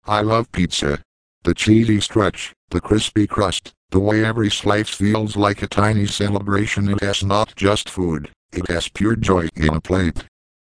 When I was growing up, computer-generated voices sounded robotic and distorted. Here's Microsoft's SAM (1998) saying how much it likes pizza.
Microsoft SAM voice that loves pizza